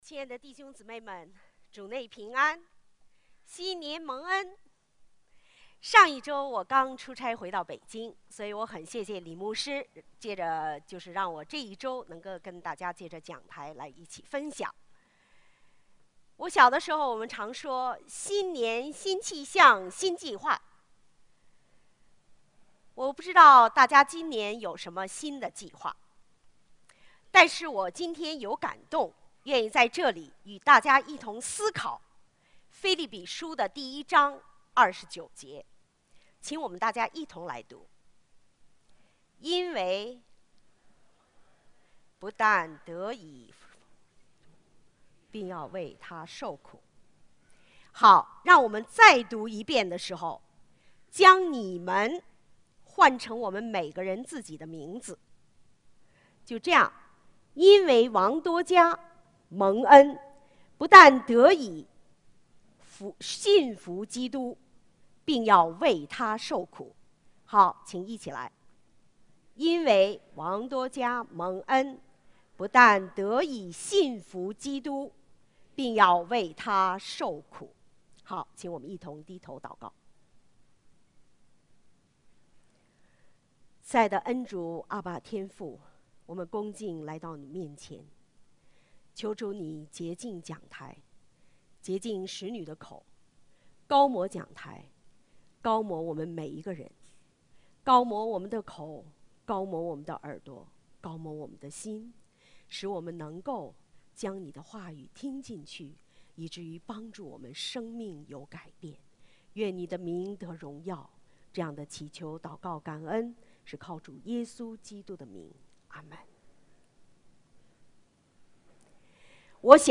主日证道 |  为基督受苦